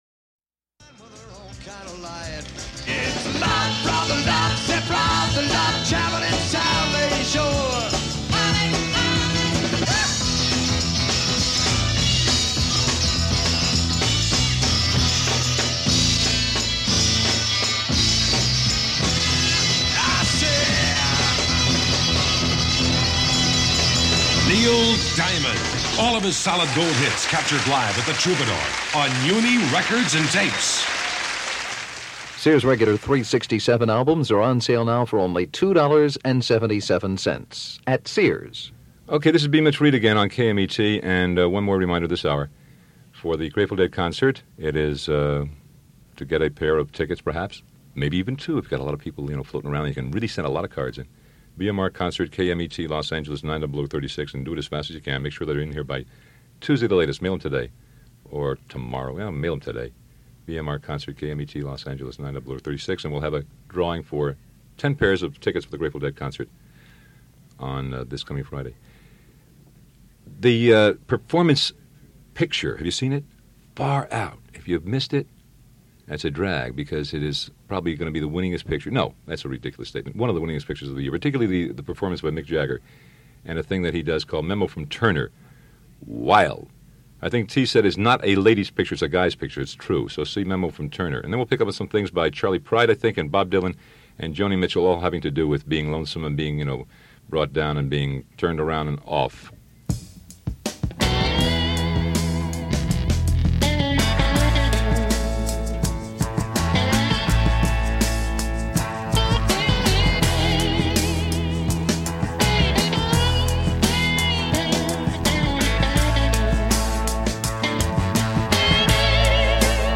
It's September 21, 1970 - You Live In L.A. - You're Back In School - Your Leader Is BMR - Past Daily Pop Chronicles,